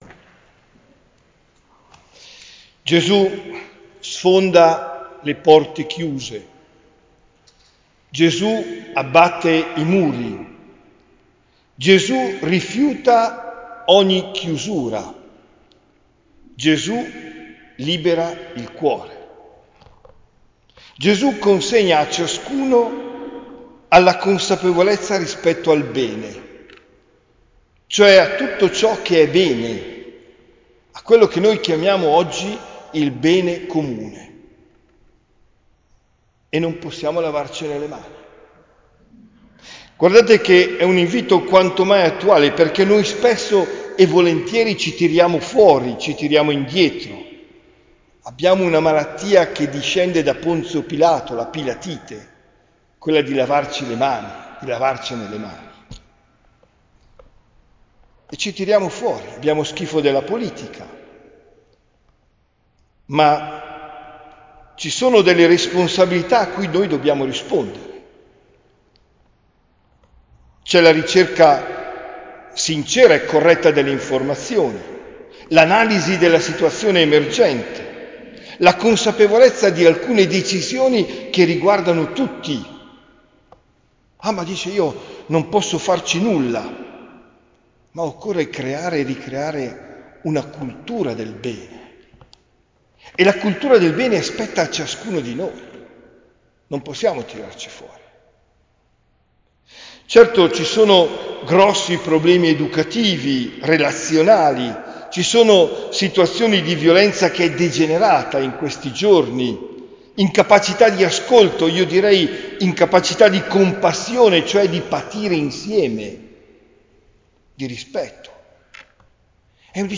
OMELIA DEL 29 SETTEMBRE 2024